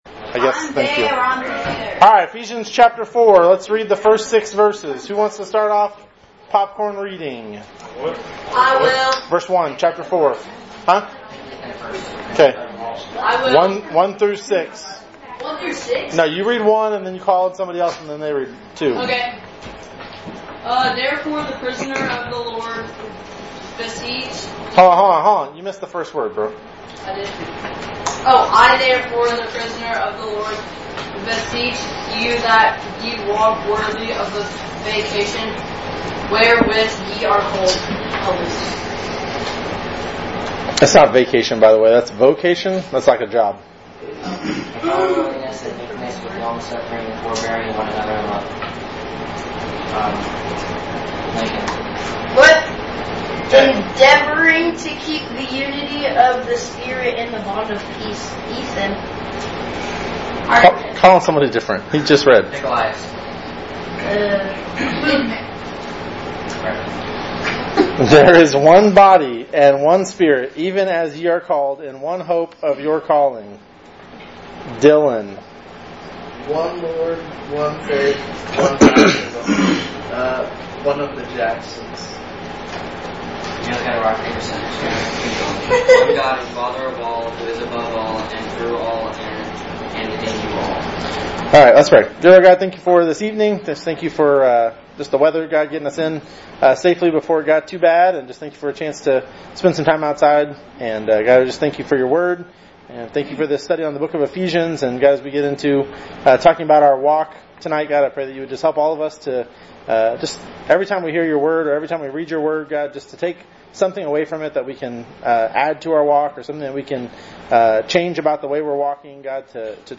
Study of Ephesians Current Sermon